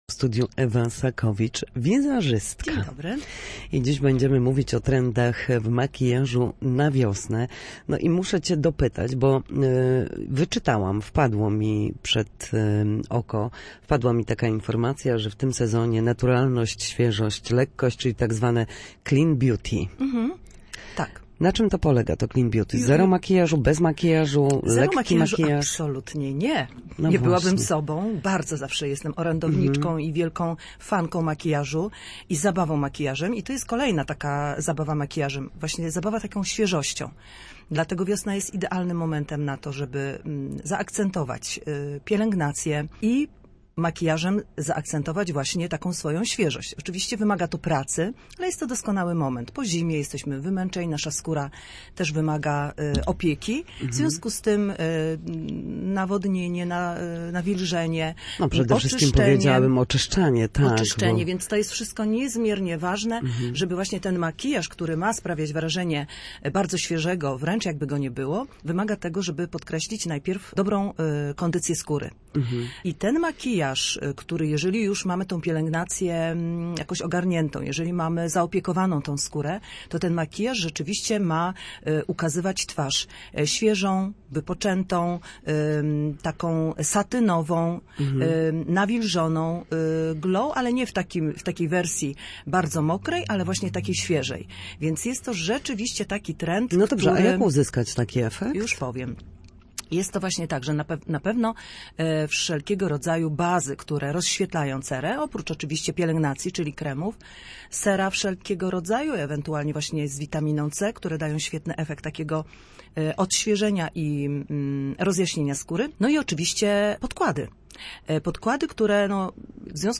Wiosenne kolory w makijażu. Wizażystka opowiada o najnowszych trendach